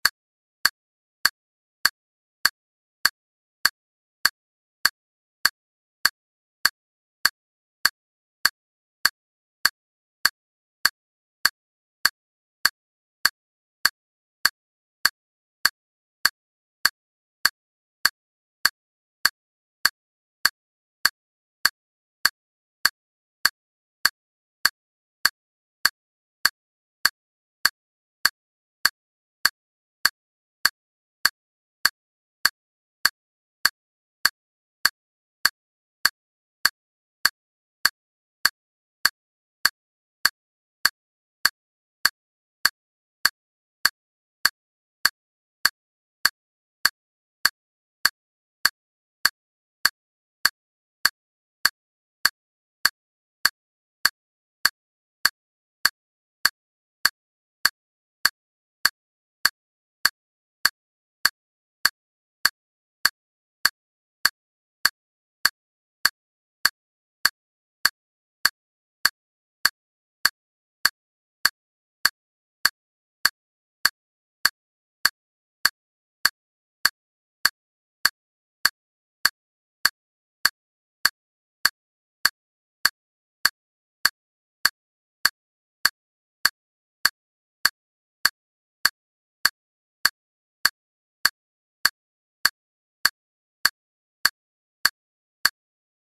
この効果音は、テンポ100で、シンプルで耳に馴染むカチカチ音が特徴。無駄のないクリアな音質がリズムのキープを助けてくれるので、練習中にしっかりとしたテンポを維持したい方におすすめです。
メトロノーム - テンポ100
シンプル
クリック音